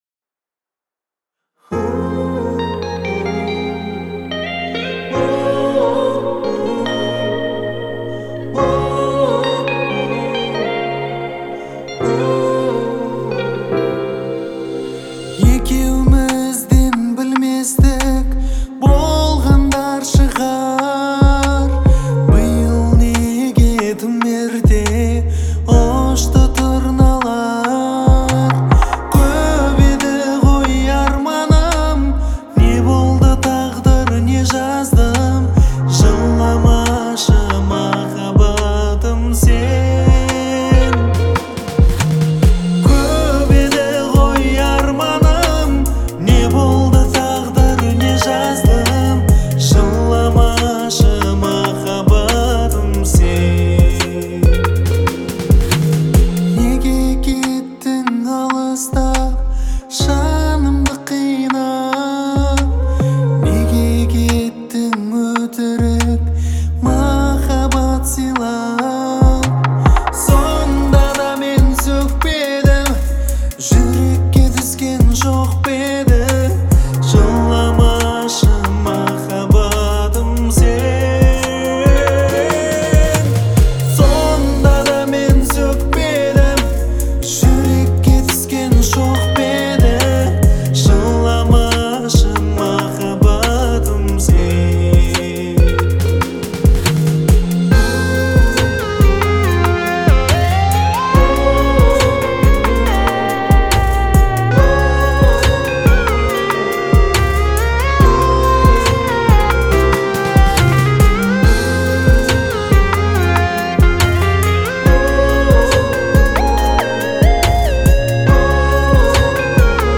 Трек размещён в разделе Поп / 2022 / Казахская музыка.